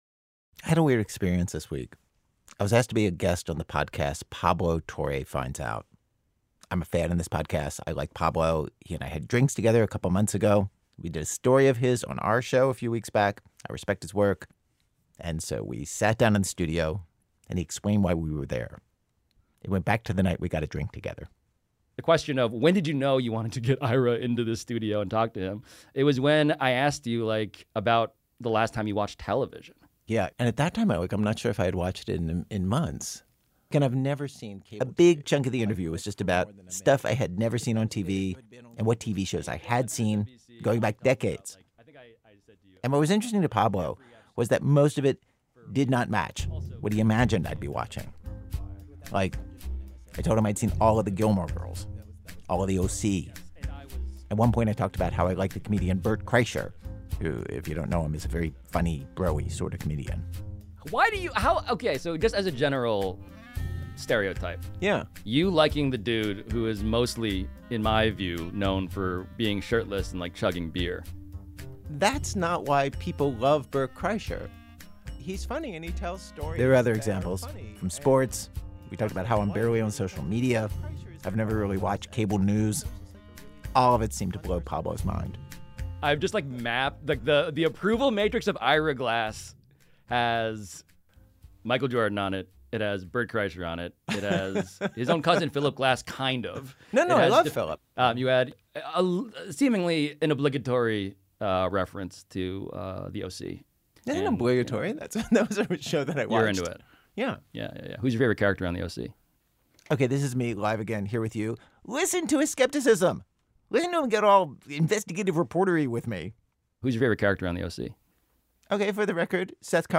Note: The internet version of this episode contains un-beeped curse words.
Host Ira Glass talks about a recent experience being interviewed and the realization that he was being asked about another version of himself.